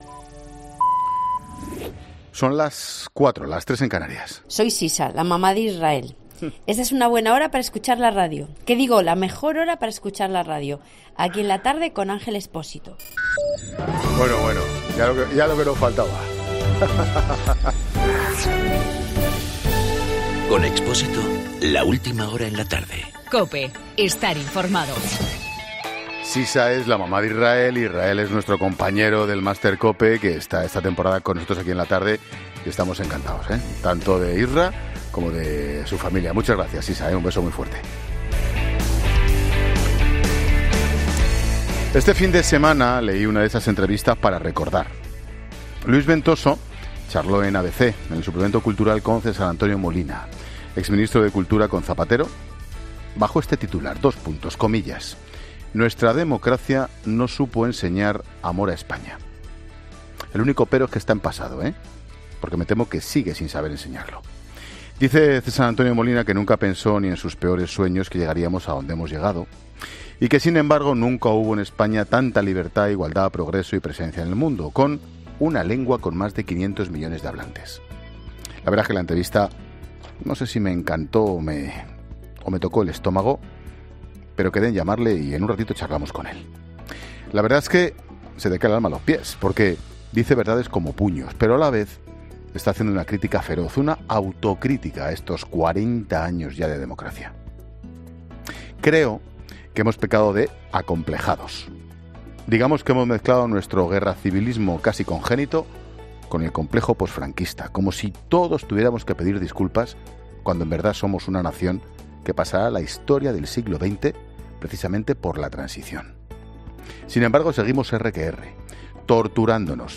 Monólogo de Expósito
Ángel Expósito analiza en su monólogo de las cuatro la situación de Cataluña a menos de una semana del 1 de octubre.